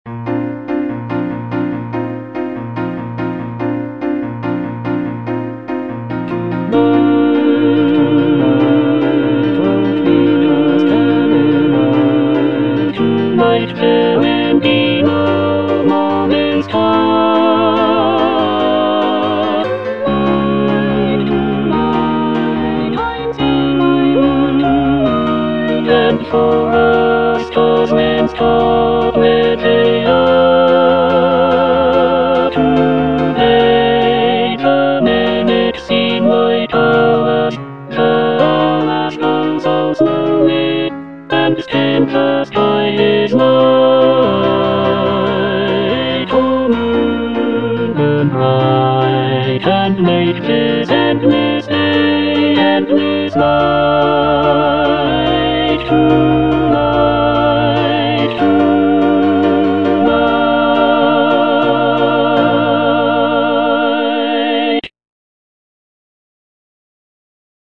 Choral selection
Tenor (Emphasised voice and other voices) Ads stop